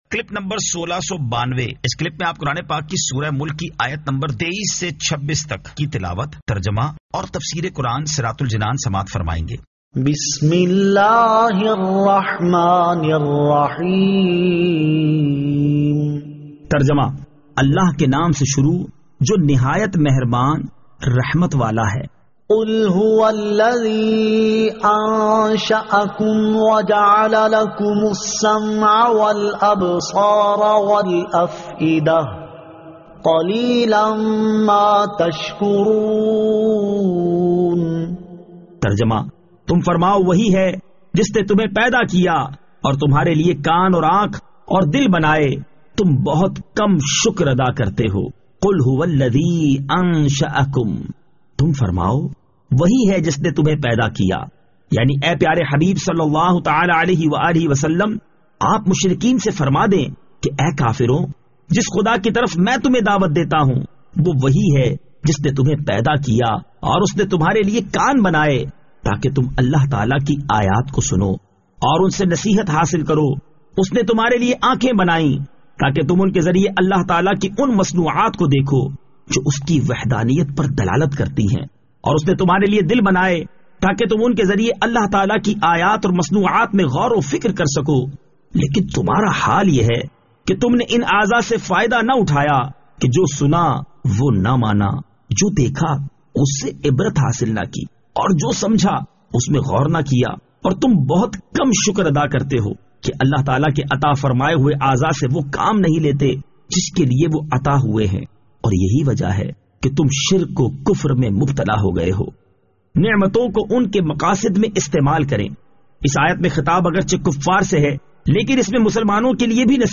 Surah Al-Mulk 23 To 26 Tilawat , Tarjama , Tafseer